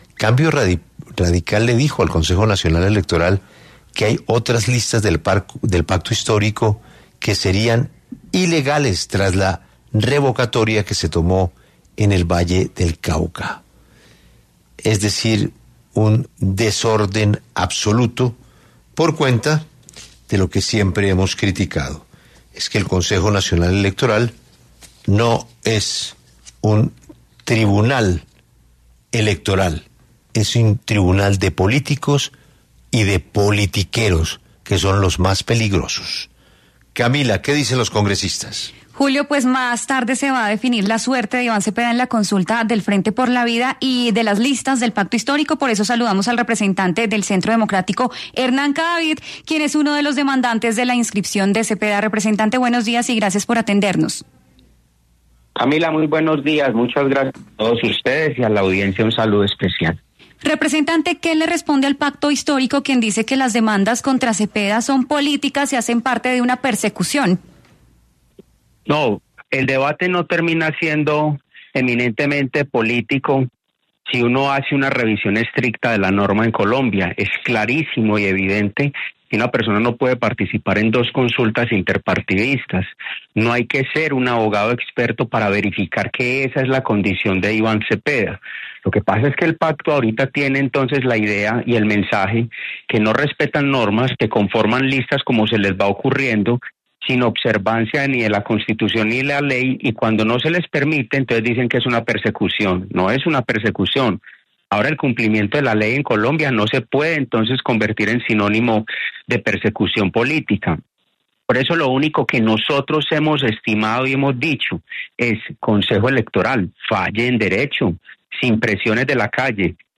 En 6AM W los representantes a la Cámara Hernán Cadavid y Gabriel Becerra hablaron sobre la demanda contra la inscripción del senador en la consulta del 8 de marzo.